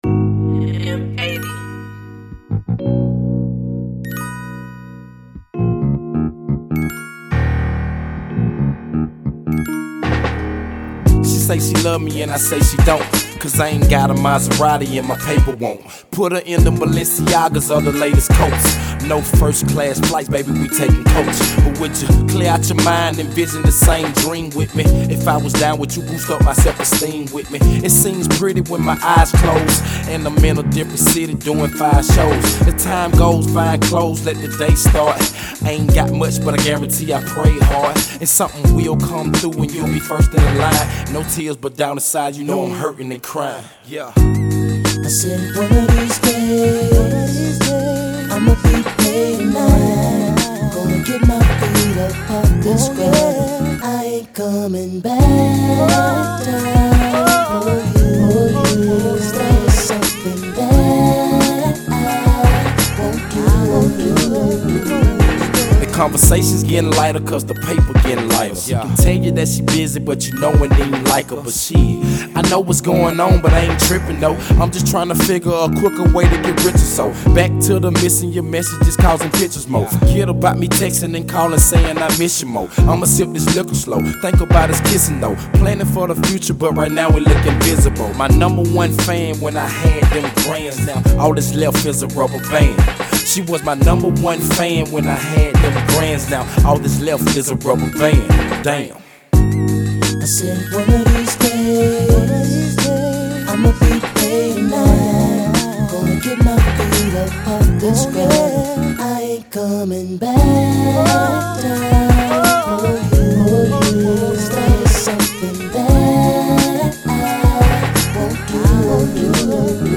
a smooth song.